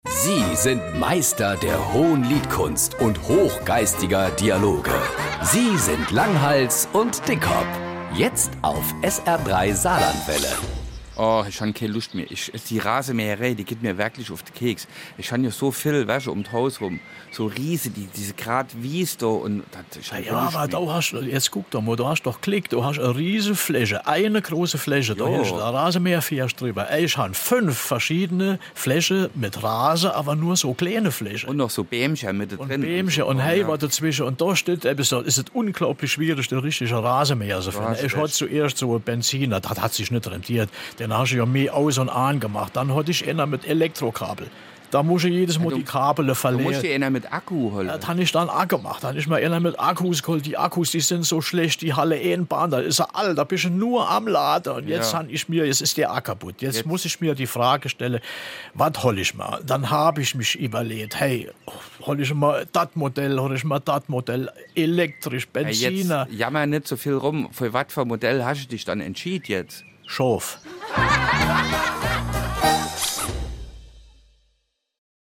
Die sprachlichen Botschafter Marpingens in der weiten saarländischen Welt.
Comedy